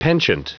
Prononciation du mot penchant en anglais (fichier audio)
Prononciation du mot : penchant